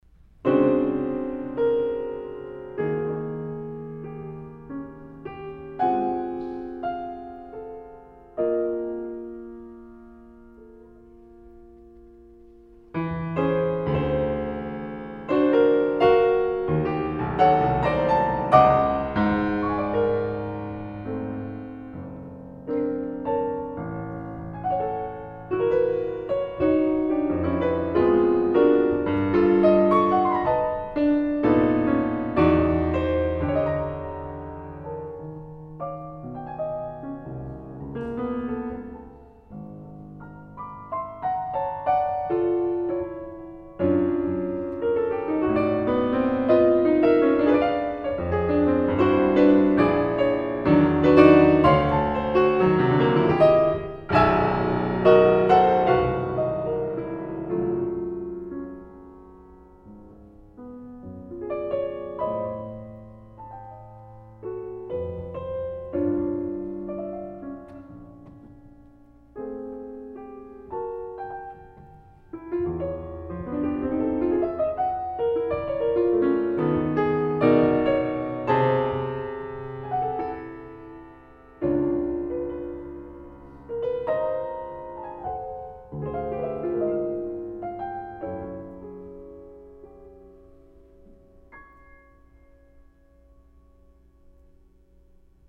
I thought I had lost the printed program from that event but I finally found it, and it appears below, along with a number of audio excerpts from the concert.